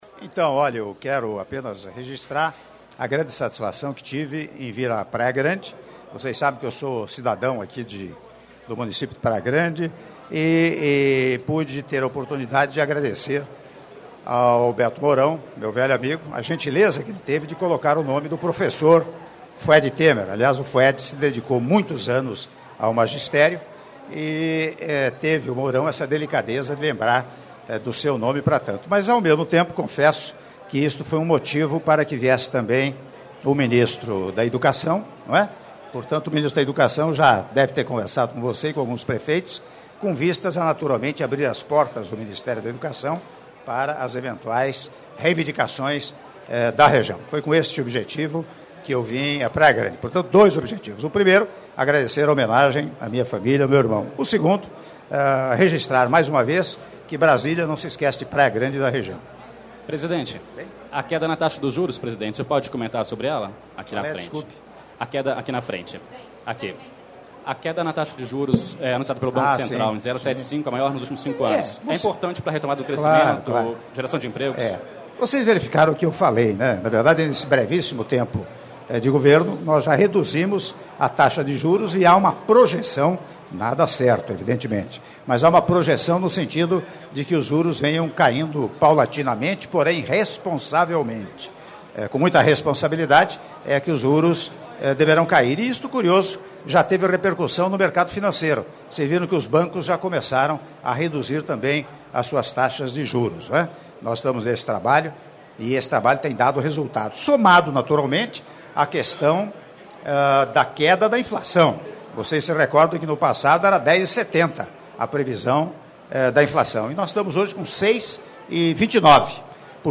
Áudio da Entrevista coletiva concedida pelo Presidente da República, Michel Temer, após solenidade de inauguração da Escola Municipal de Ensino Fundamental Professor Fued Temer - Praia Grande/SP (06min09s)